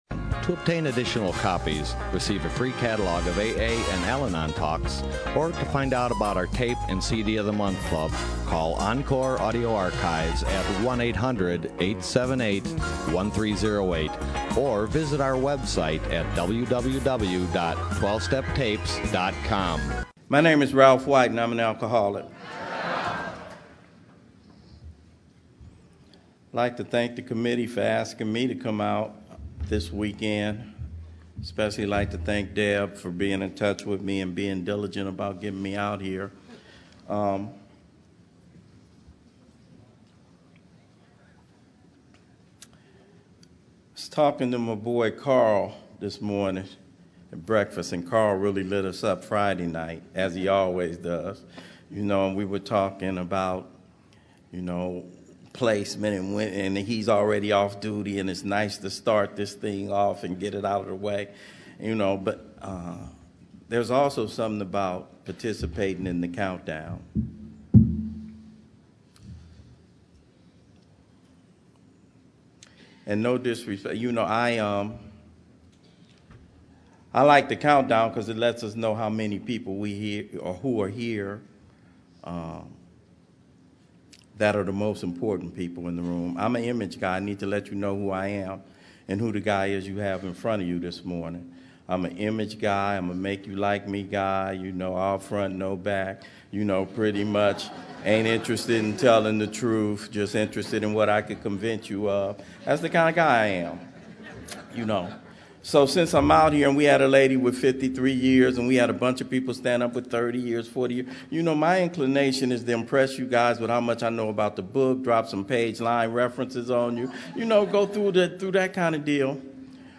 Orange County AA Convention 2015